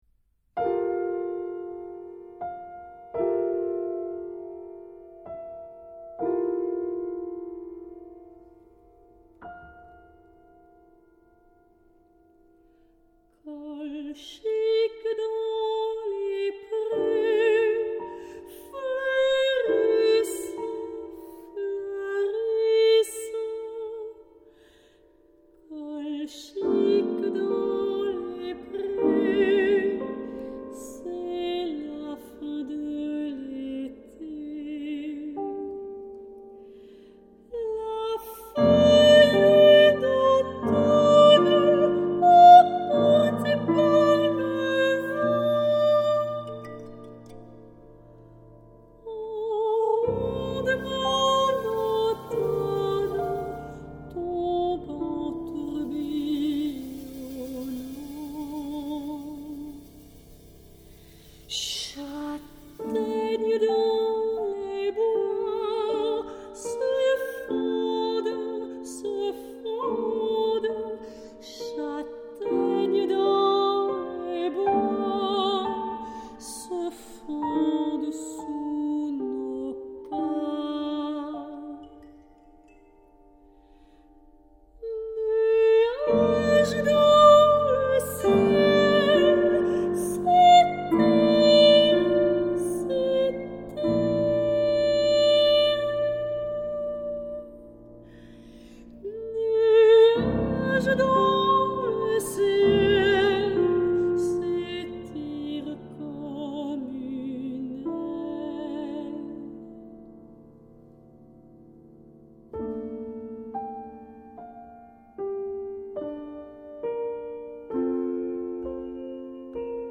sopraan
piano